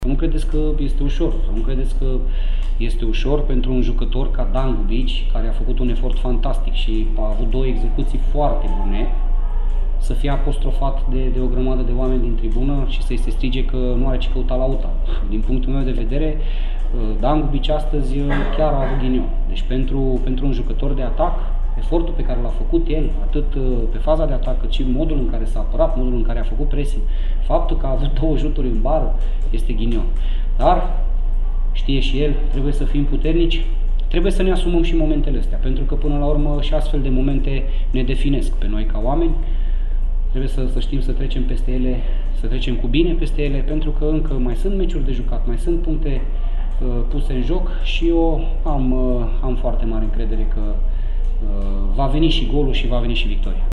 Despre ineficiența din fața porții și despre… ghinion a vorbit, printre altele, la finalul meciului, Laszlo Balint: